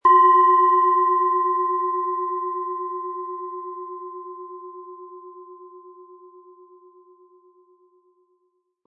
Planetenton 1
Lieferung inklusive passendem Klöppel, der gut zur Planetenschale passt und diese sehr schön und wohlklingend ertönen lässt.